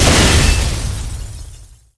bombexplode.wav